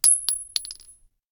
Divergent/pistol_generic_4.ogg at 57e0746fe6150be13274d27e72deb22ed2c7620f
pistol_generic_4.ogg